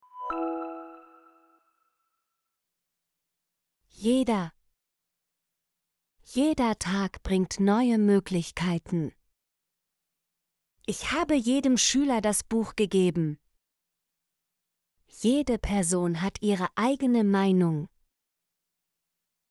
jeder - Example Sentences & Pronunciation, German Frequency List